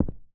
carpet_1.ogg